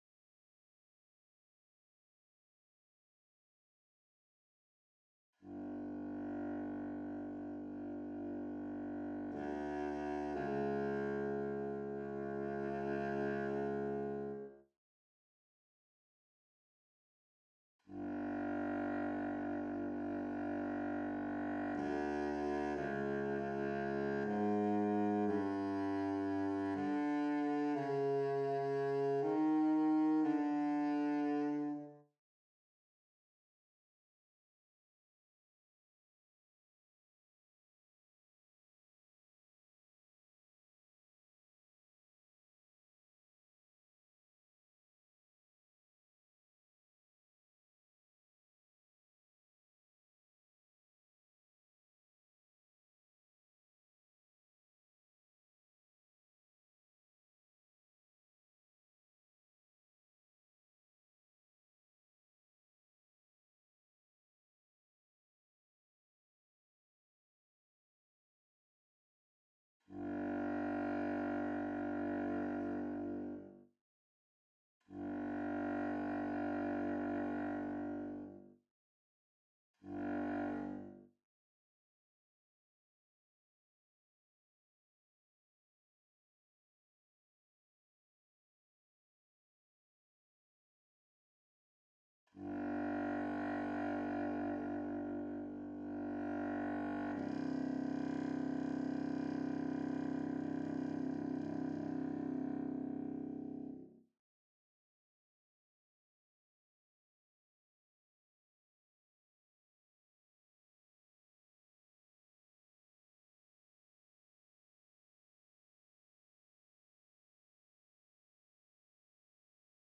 9. Bassoon (Contrabassoon/Normal)
Holst-Mars-38-Contrabassoon_0.mp3